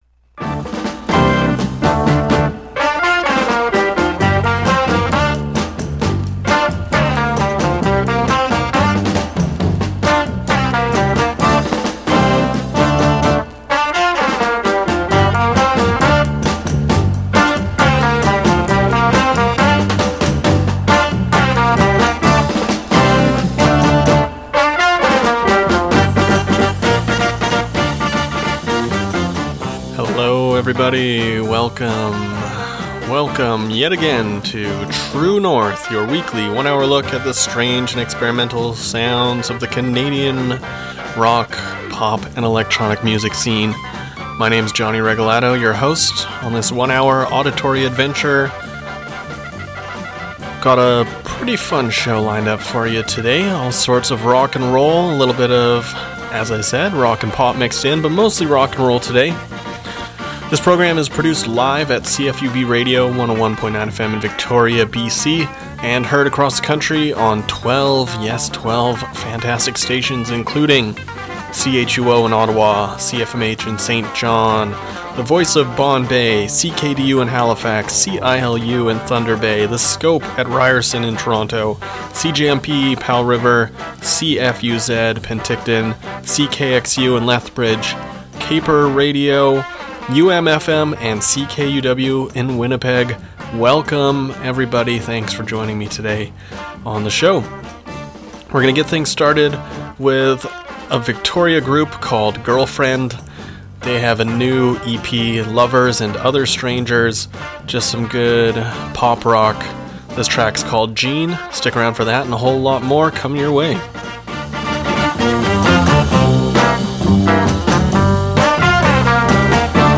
An hour of strange, experimental and independent Canadian rock and pop